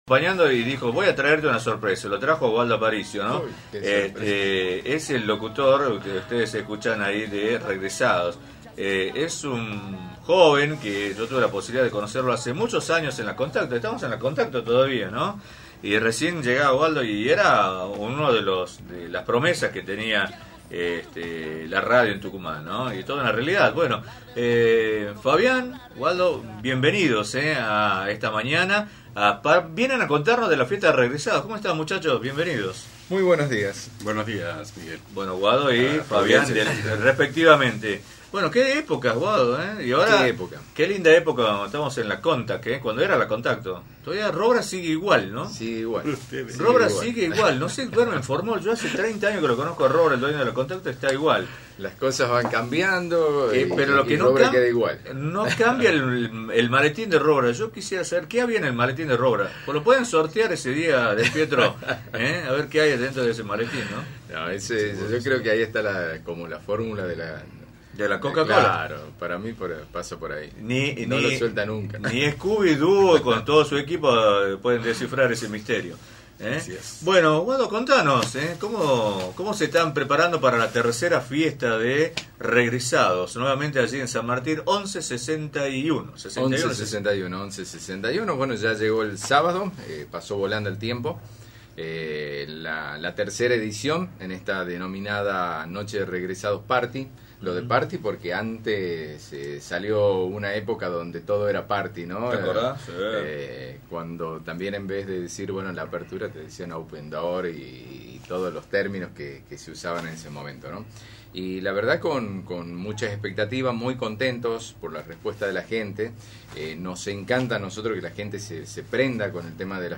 visitaron los estudios de la radio